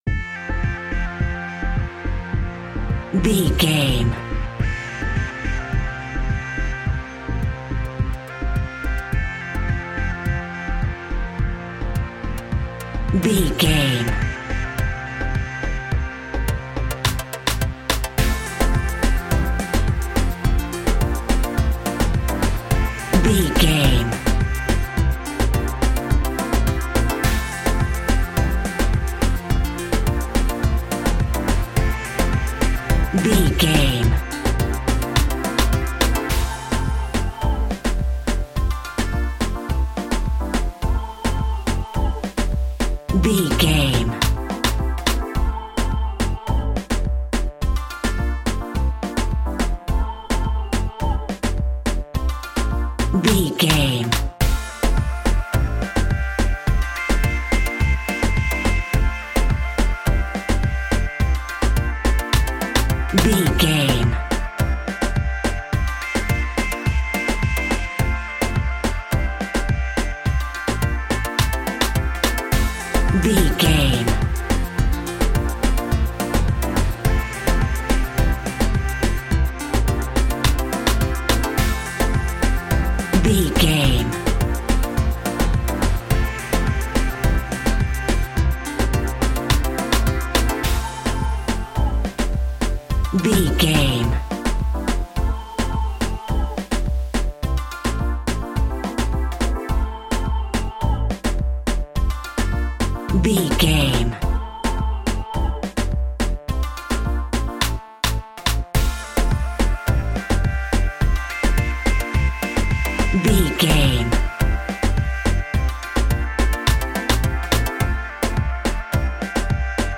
Classic reggae music with that skank bounce reggae feeling.
Ionian/Major
dub
laid back
chilled
off beat
drums
skank guitar
hammond organ
percussion
horns